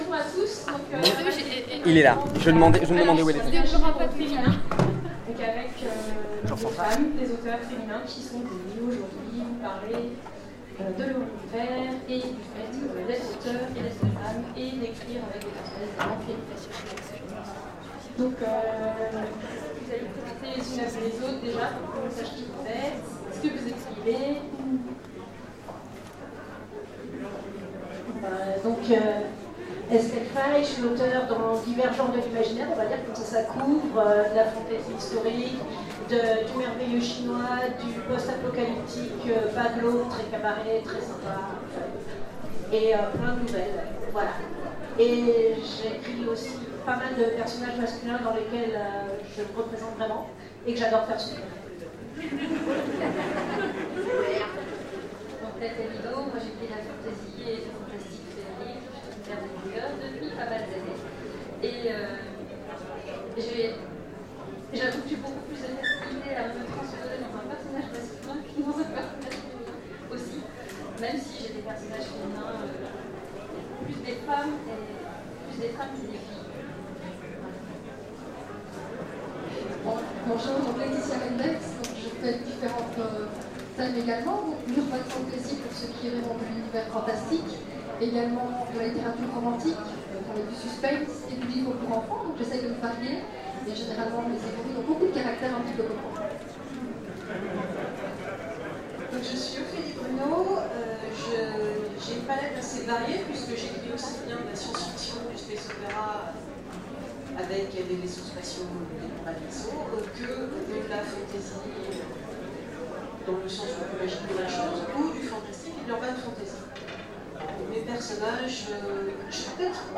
Salon Fantastique 2016 : Conférence L’imaginaire au féminin